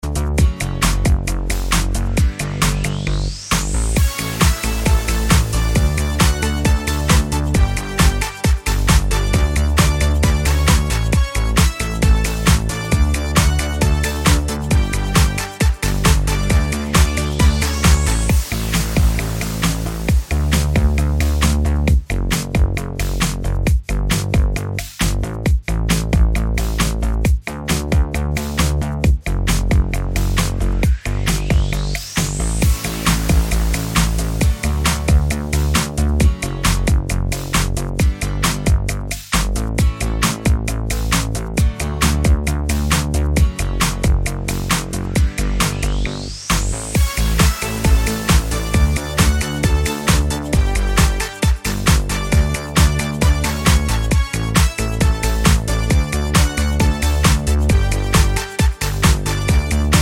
no Backing Vocals Comedy/Novelty 2:47 Buy £1.50